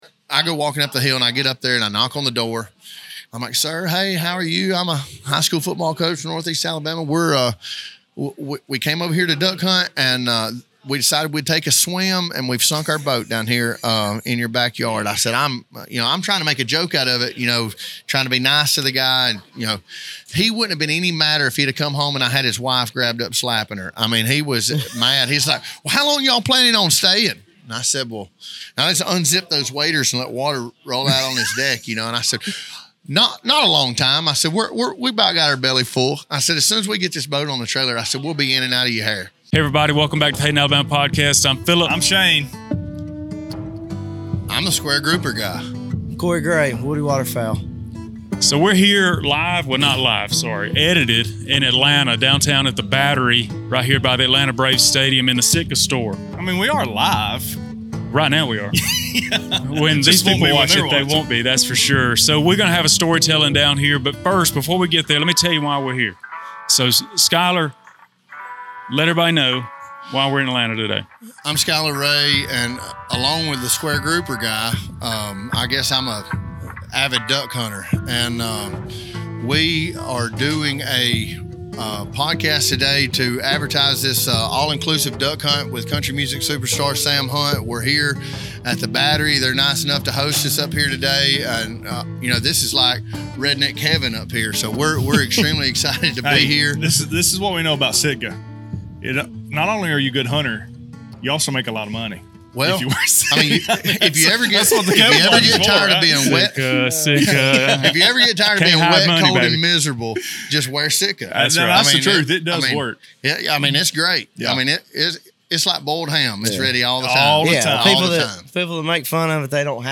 We drove over to Atlanta, Georgia to record a bonus episode at the Sitka store in the Battery. Get ready to hear some great stories from Washington all the way to New York City.